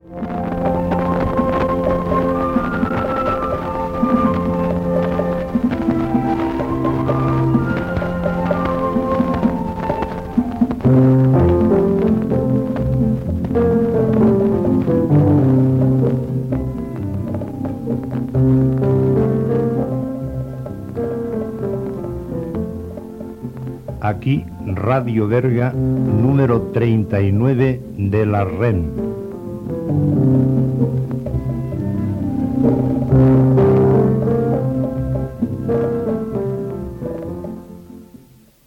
Música i identificació
Fragment extret de l'emissió inaugural de Ràdio Berga Cadena 13, feta el dia 29 de maig de 1986.